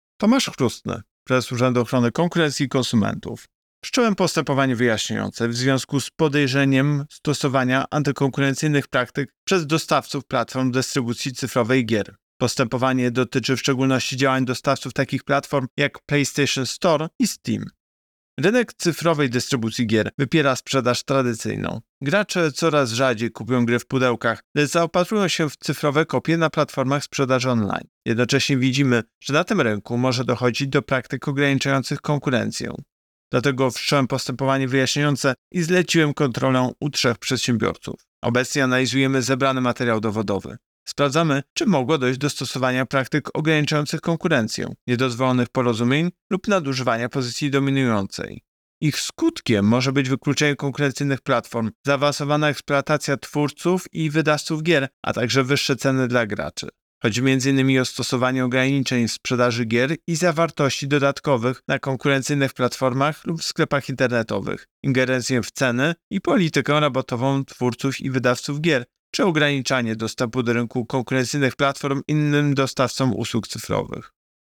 Wypowiedź Prezesa UOKiK Tomasza Chróstnego Kontrola UOKiK odbyła się w siedzibie jednej ze spółek z grupy Sony, a także u dwóch twórców i wydawców gier wideo w Polsce.